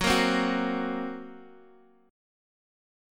GbMb5 chord